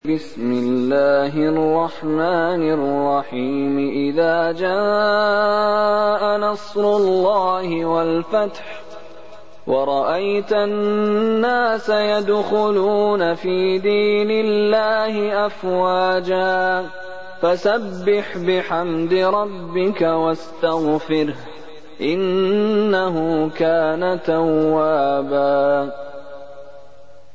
منتخب تلاوتهای شیخ مشاری العفاسی